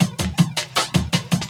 12 LOOP14 -L.wav